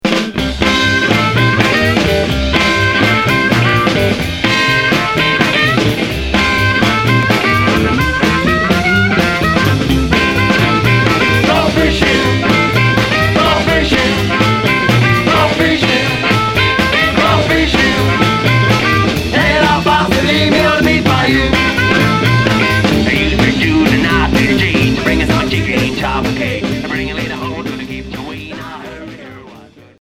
Rockabilly